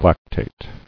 [lac·tate]